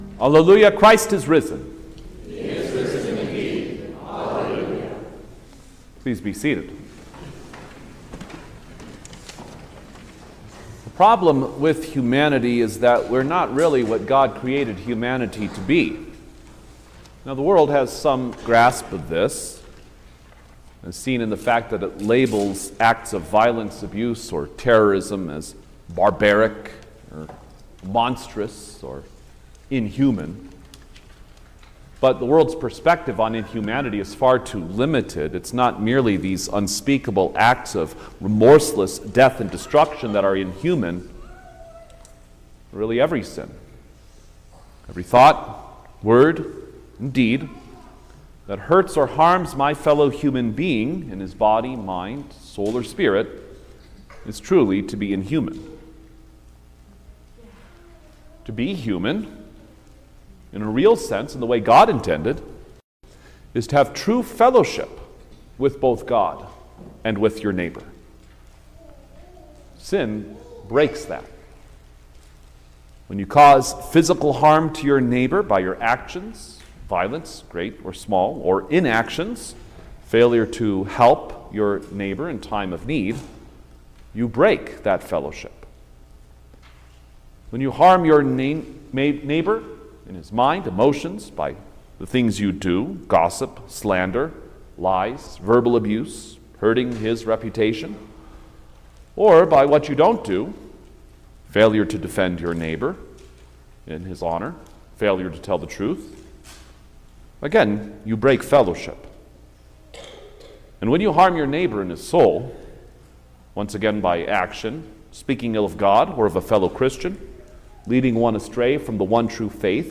June-1_2025_Seventh-Sunday-of-Easter_Sermon-Stereo.mp3